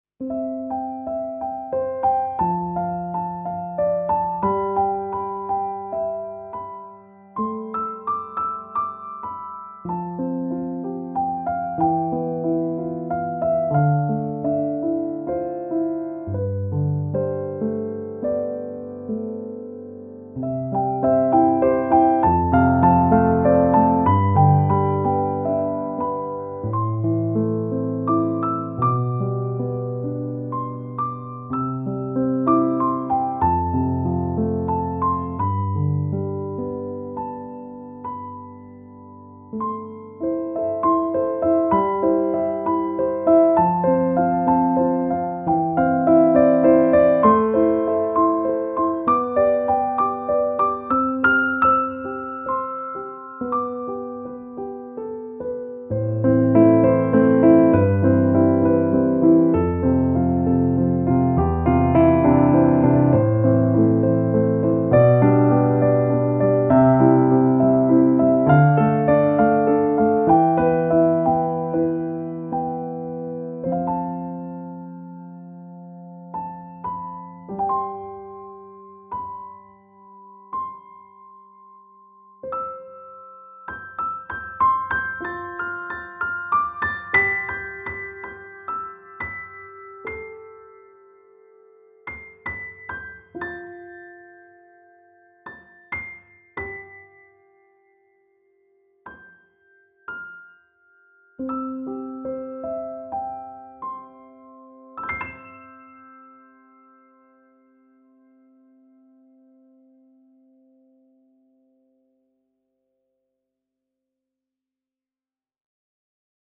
Voicing/Instrumentation: Piano Solo